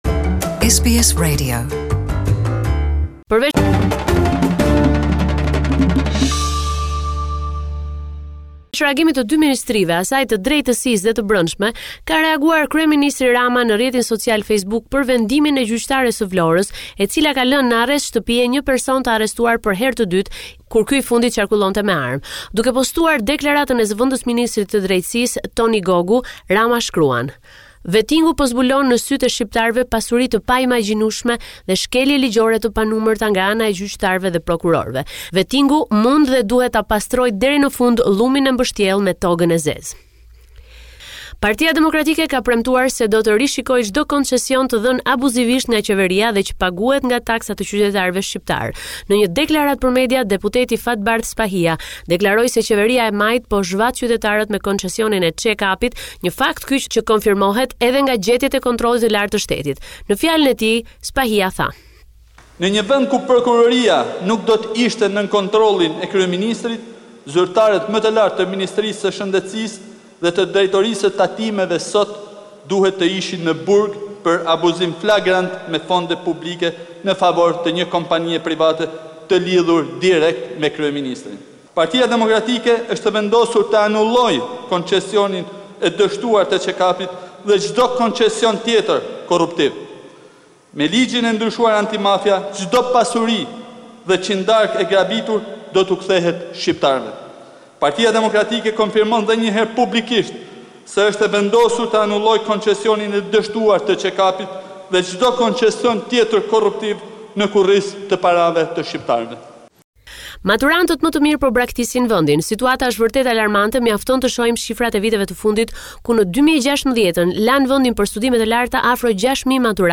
This is a report summarising the latest developments in news and current affairs in Albania